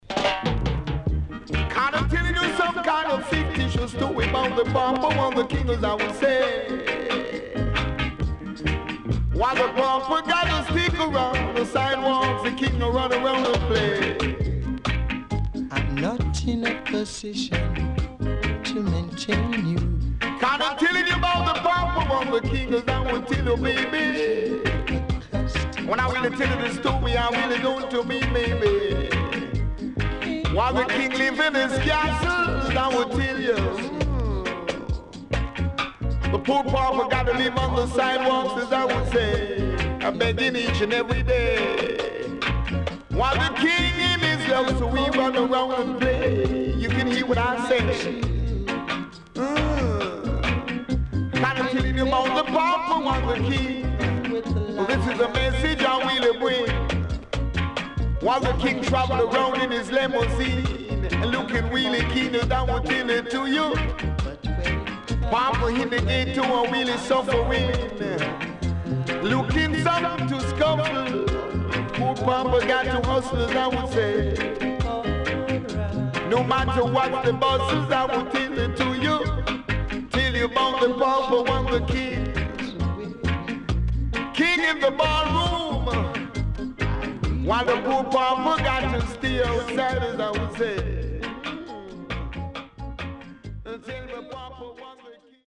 HOME > REGGAE / ROOTS  >  70’s DEEJAY
SIDE B:所々チリノイズがあり、少しプチノイズ入ります。